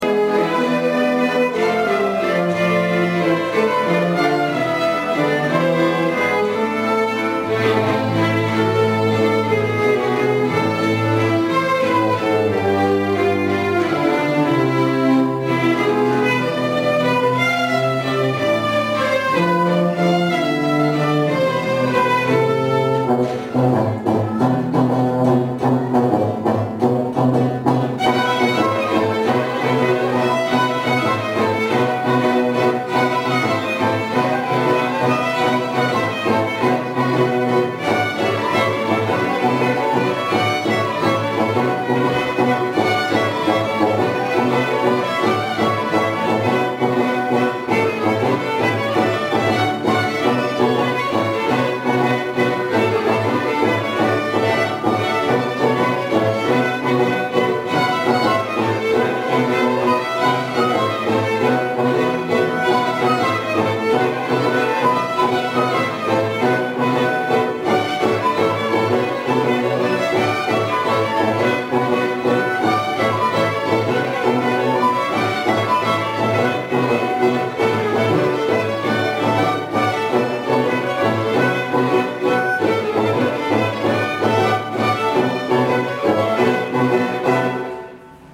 Violons, accordéons, trombones, flûtes et guitares ont résonné dans la Maison du parc de Vallouise mercredi dernier.
Les musiques entrainantes et les petites histoires ont réchauffé les cœurs !